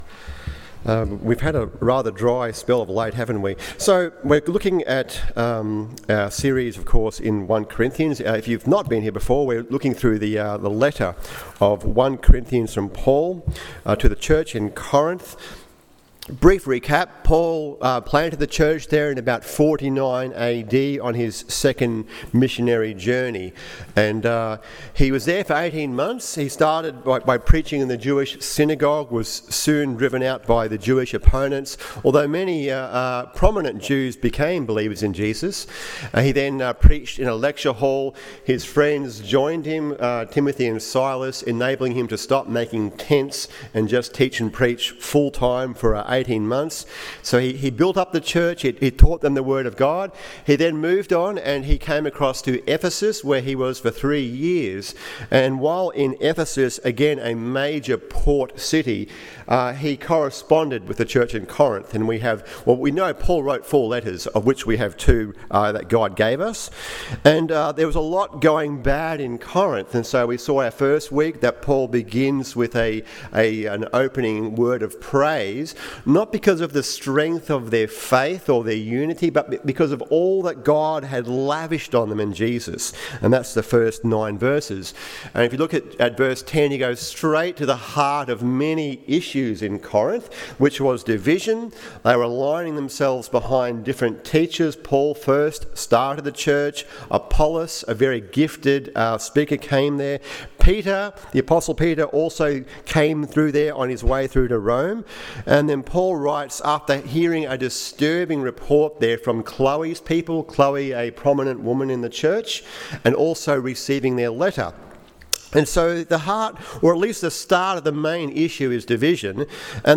Do You Know The Power Of The Cross? AM Service